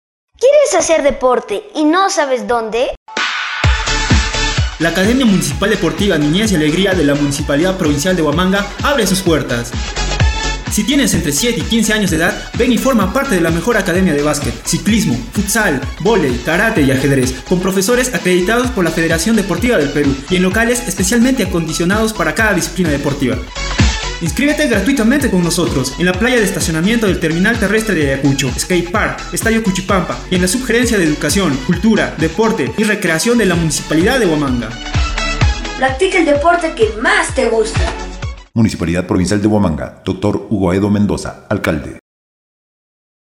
Spot Radial